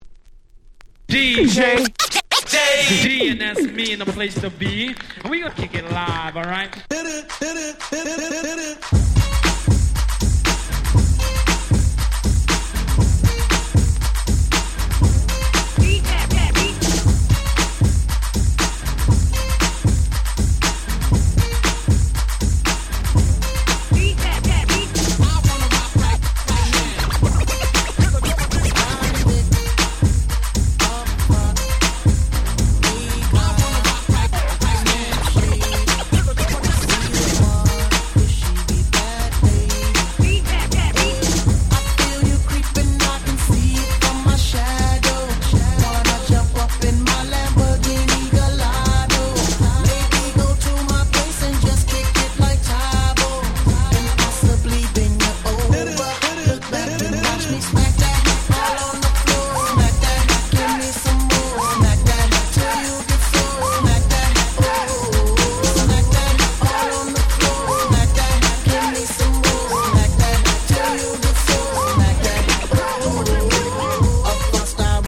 全6曲全部アゲアゲ！！